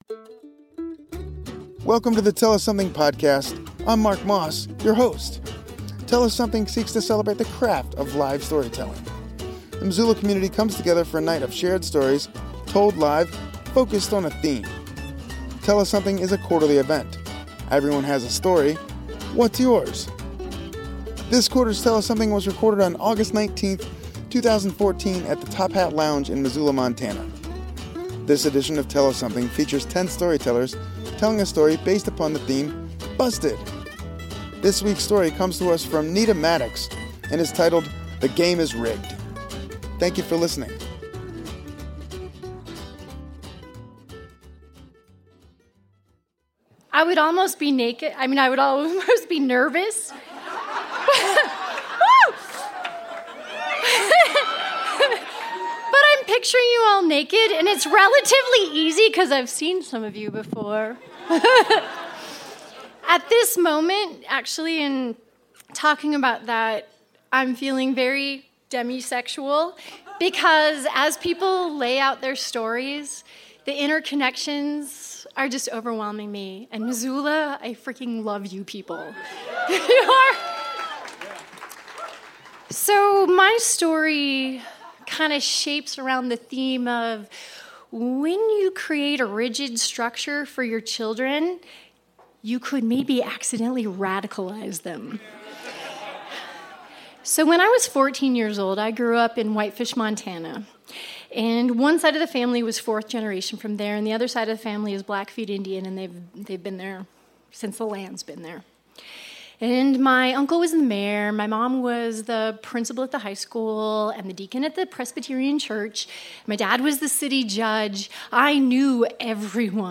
This edition of Tell Us Something was recorded on August 19th, 2014 at the Top Hat Lounge in Missoula, MT. The theme was “Busted!”.